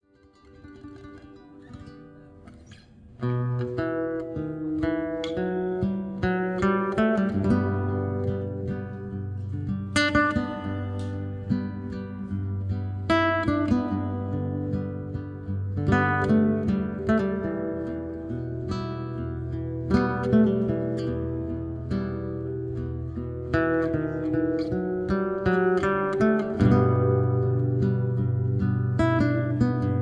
Soothing and Relaxing Guitar Music